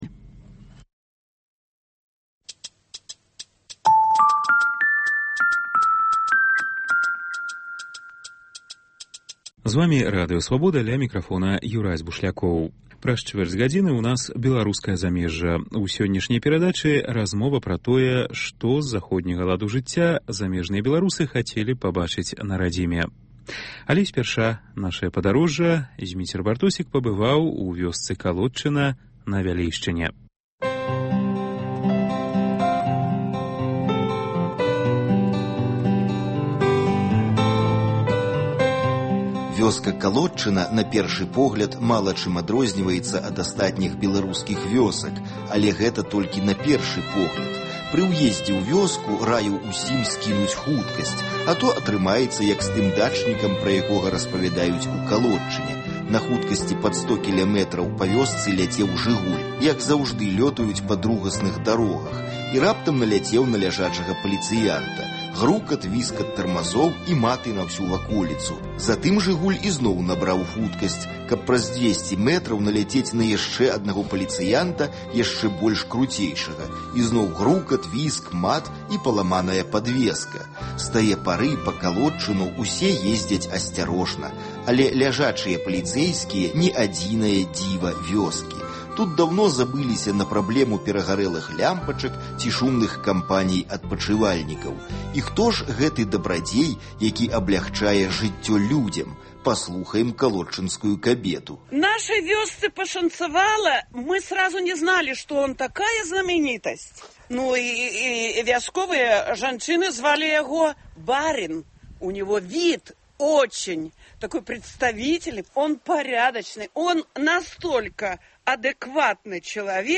Паездкі нашых карэспандэнтаў па гарадах і вёсках Беларусі.